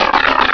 -Replaced the Gen. 1 to 3 cries with BW2 rips.
mawile.aif